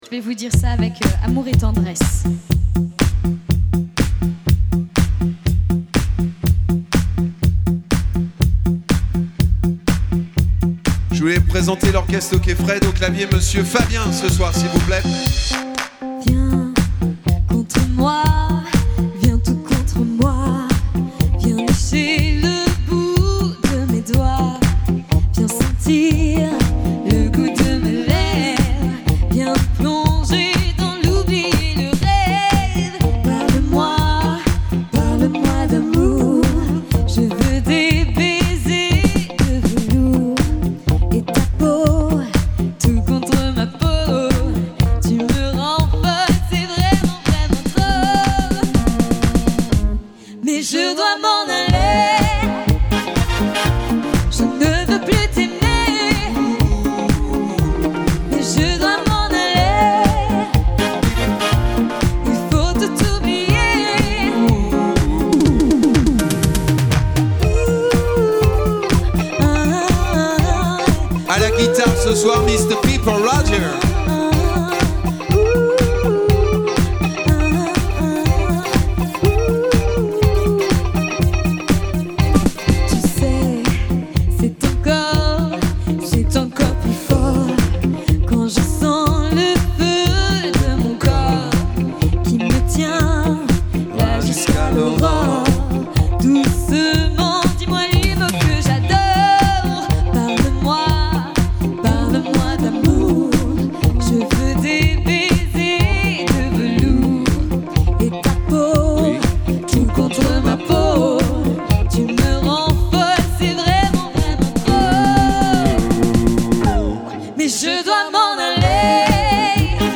Programme d'orchestre de variété avec chanteuse et chanteur
Chant Batterie, Chant & Guitare Piano & Clavier